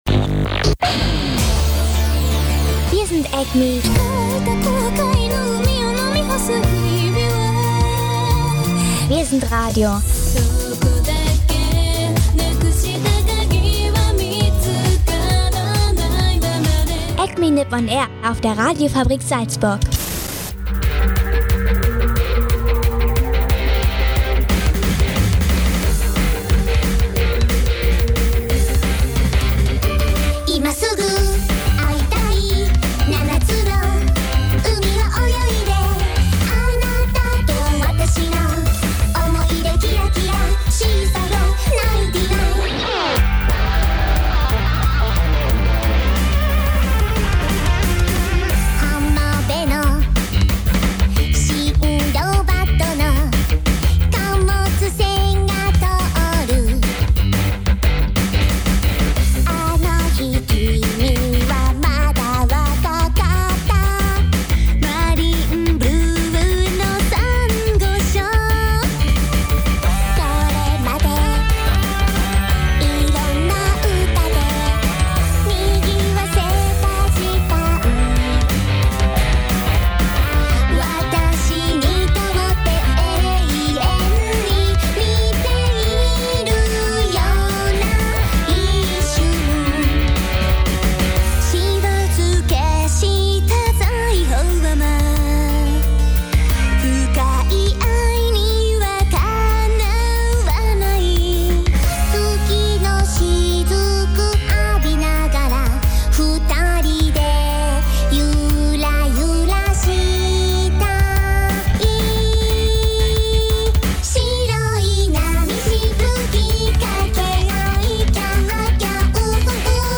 Dazu gibts natürlich Anime- und Japan-News und viel Musik aus Japan.